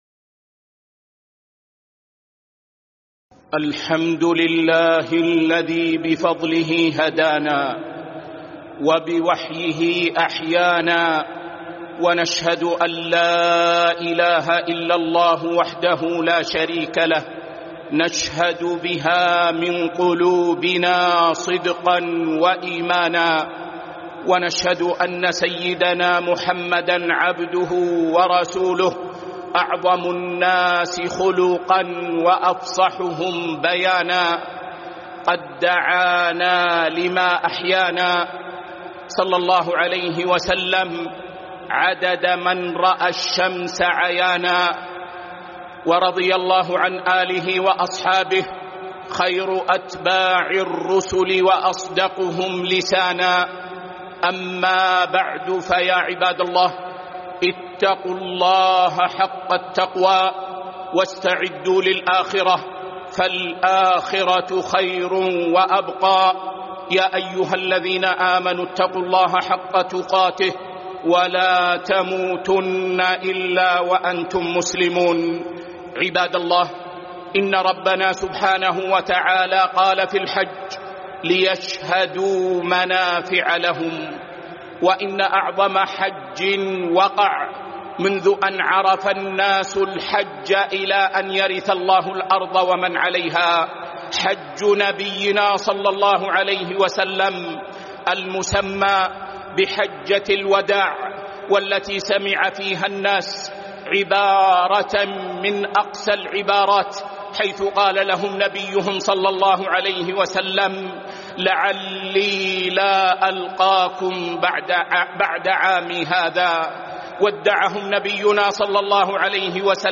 خطبة
مسجد قباء